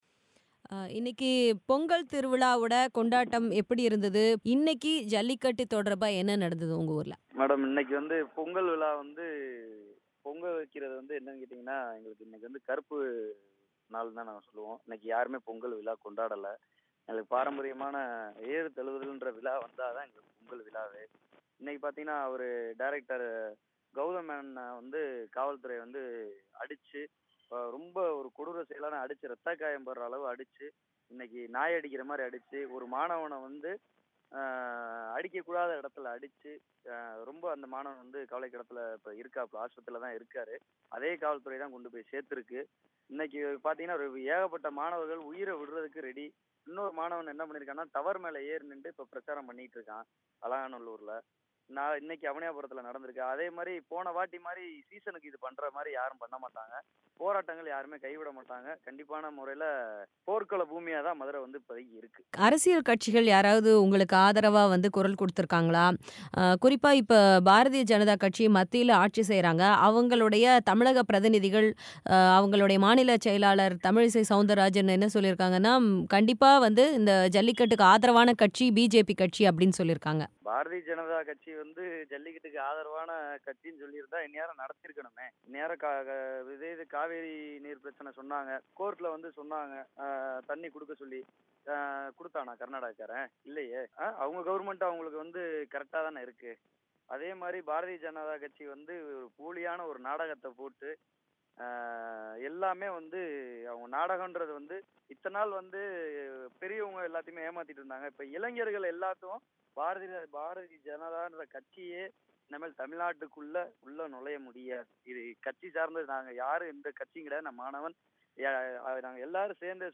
அவர் பிபிசி தமிழுக்கு அளித்த பேட்டி.